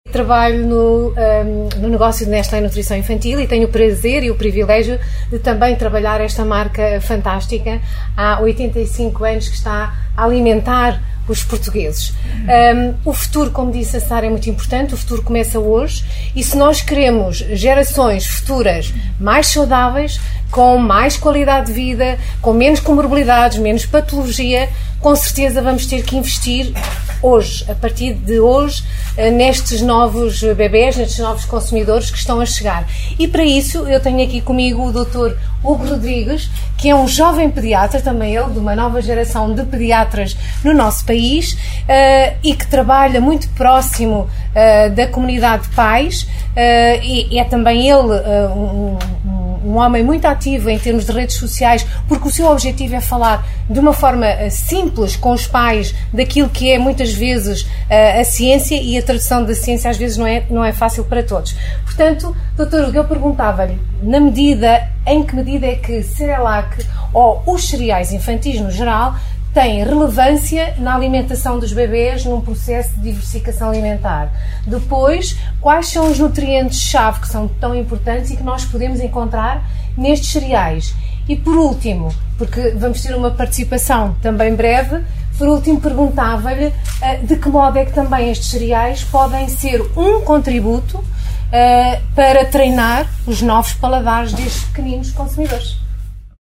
Após estas introduções, que pode ouvir na integra clicando nos players respetivos, a organização das celebrações levou os convidados a visitar parte das instalações fabris, num esforço de bem receber revestido de uma logística complexa, no intuito de garantir a segurança máxima, apanágio de toda a organização.